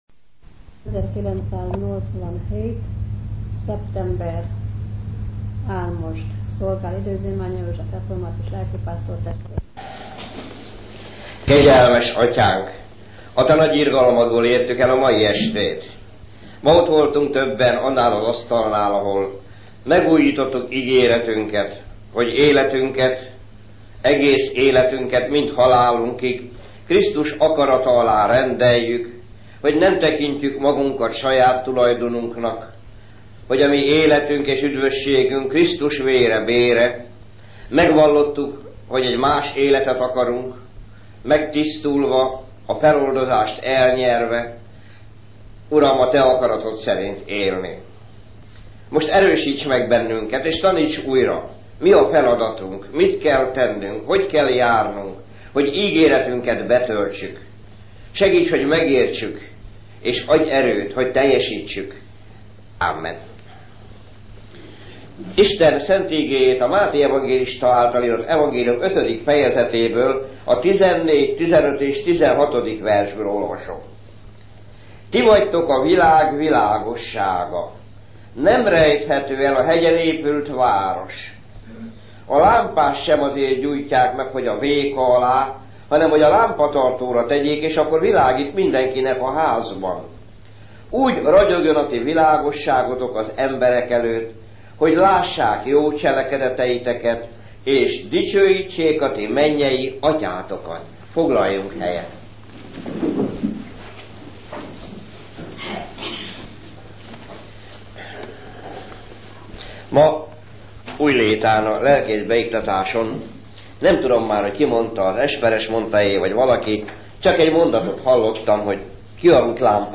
'Fényben, a teljes délig' című igehirdetése